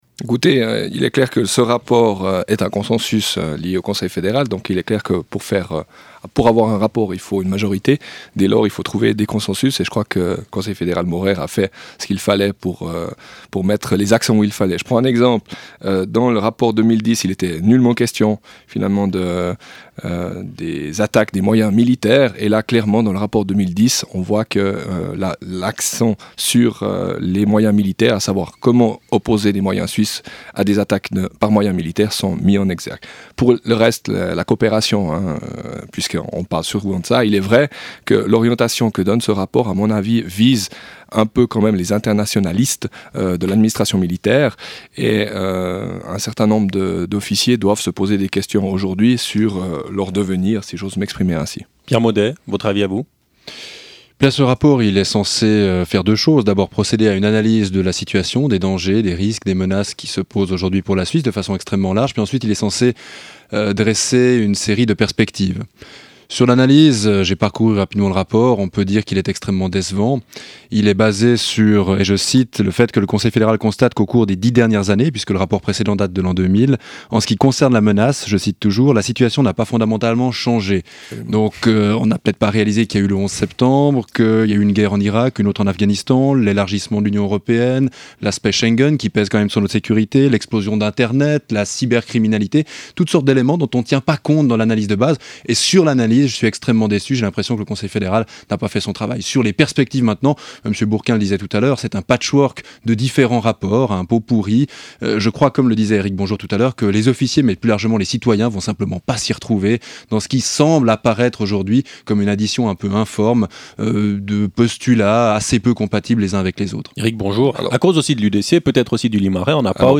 (RSR) Débat entre deux politiciens et officiers de milice